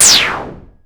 Shoot08.wav